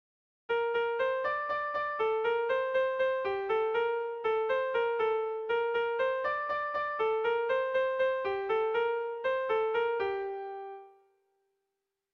Erromantzea
A1A2